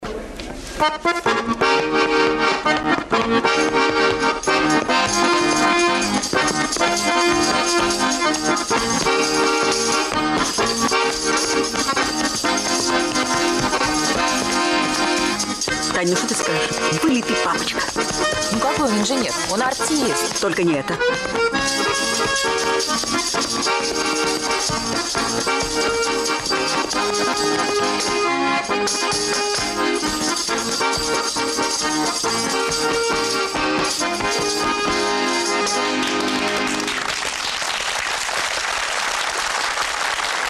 время 33:18 (танец "степ")